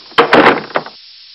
دانلود صدای در 3 از ساعد نیوز با لینک مستقیم و کیفیت بالا
جلوه های صوتی